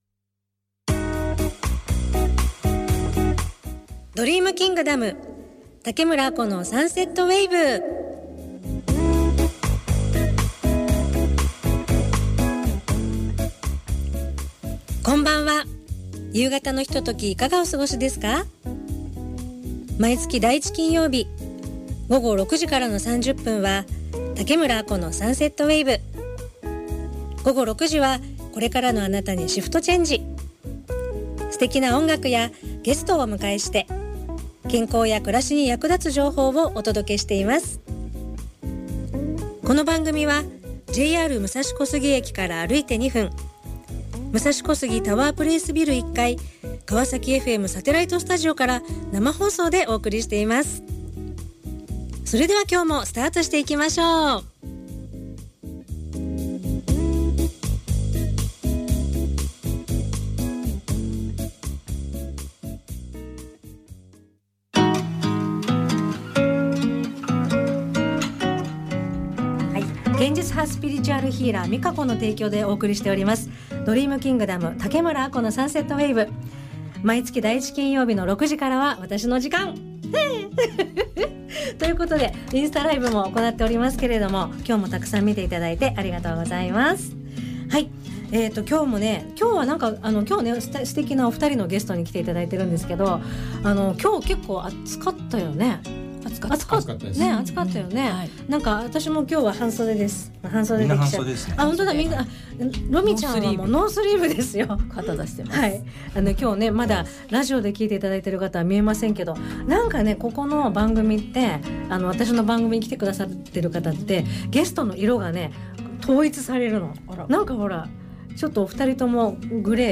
＊かわさきFMサテライトスタジオから生放送